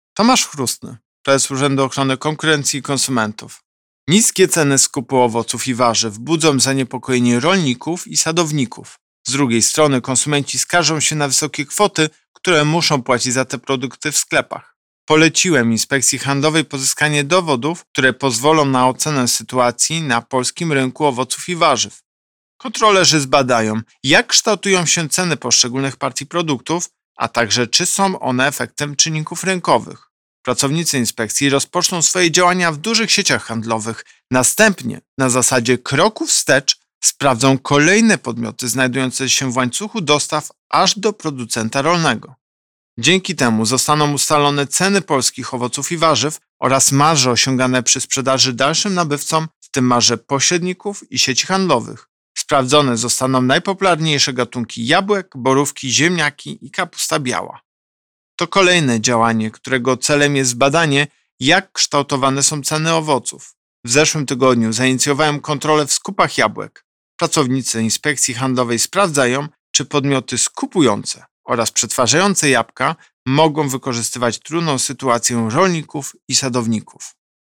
Wypowiedź Prezesa UOKiK Tomasza Chróstnego z 7 września 2021 r..mp3